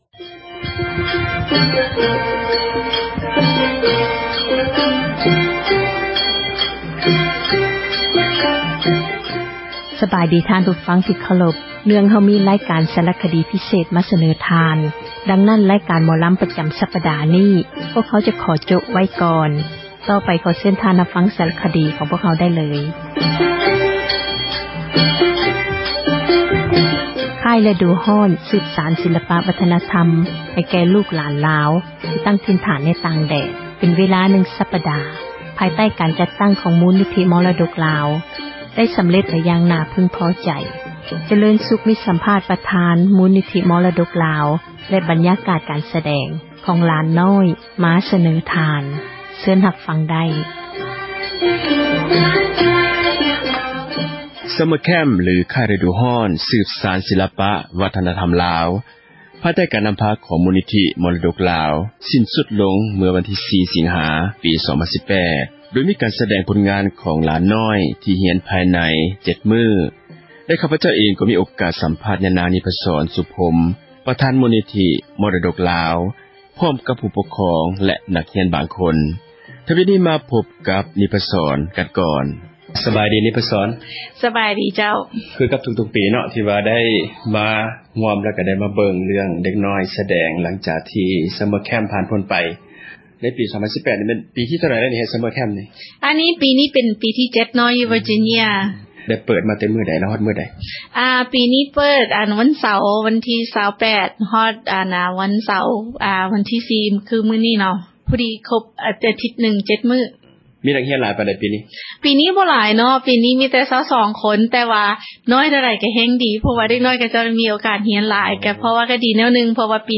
ບັນຍາກາດ ການສະແດງ ຂອງຫລານນ້ອຍ ມາສເນີທ່ານ.